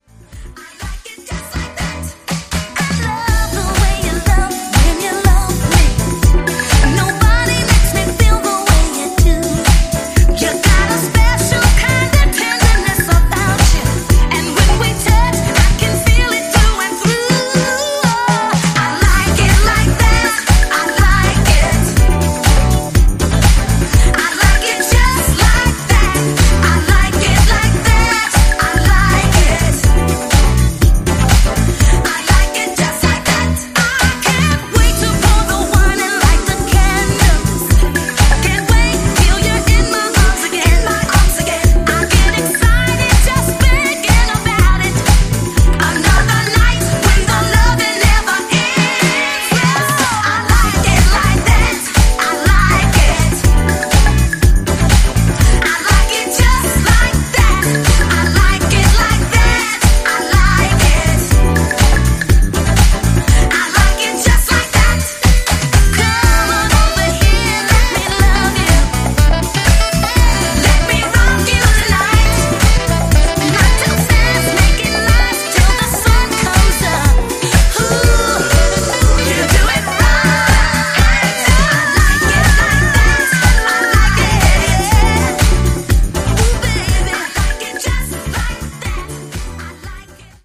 原曲を尊重しながらハウス仕立てにしたA面